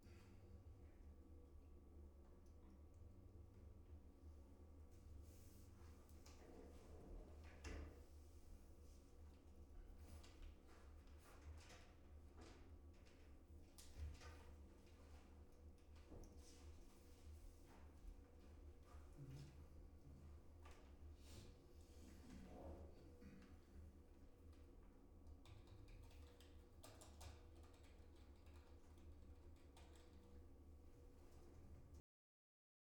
Additionally, it puts the adaptive nature of some ANC systems to the test, as the noise in these tests isn't constant and contains transient sounds like phones ringing and large vehicles accelerating.
You may need to raise your device's volume to distinguish additional details since our output recordings aren't very loud.
Office Noise
office-noise-sample.wav